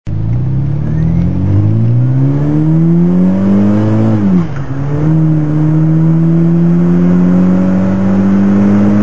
3000rpmあたりが、騒音のピークでしょう。
サウンド 0〜200m加速 (103KB 21sec mp3)